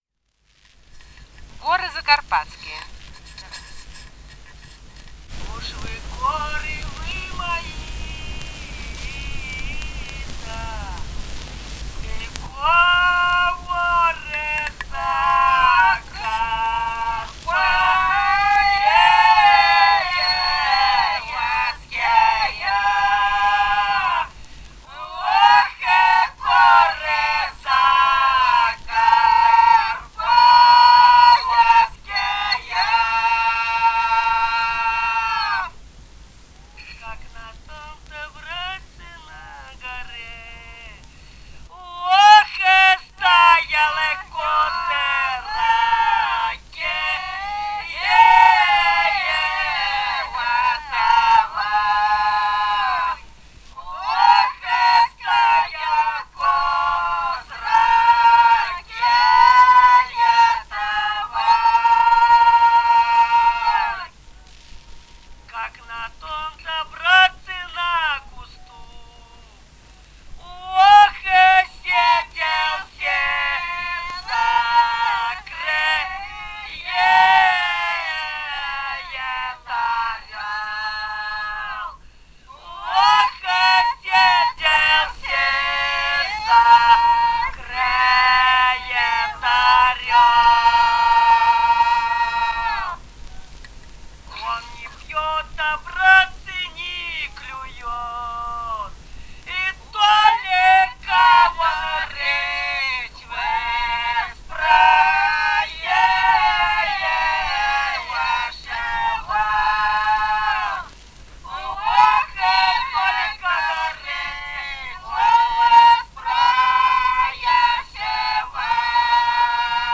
Этномузыкологические исследования и полевые материалы
«Уж вы горы вы мои-то» (лирическая беседная).
Бурятия, с. Желтура Джидинского района, 1966 г. И0904-08